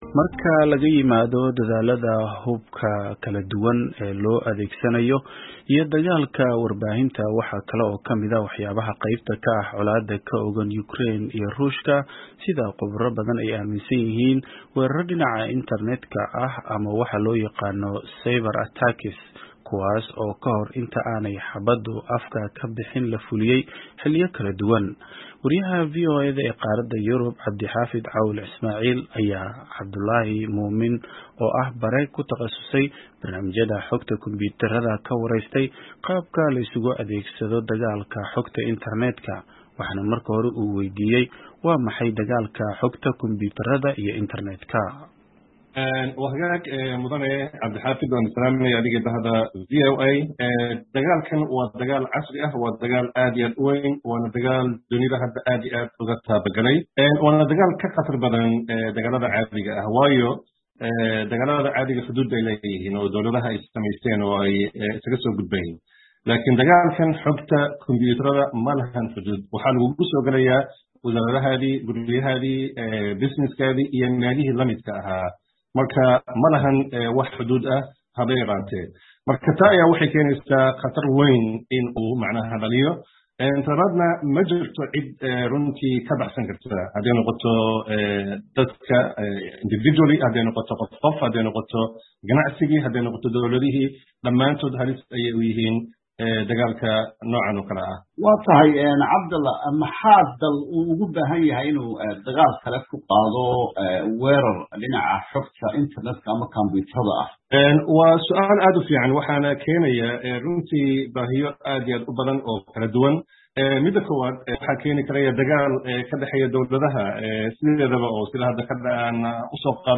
Wareysi ku saabsan dagaalka aan tooska aheynUkraine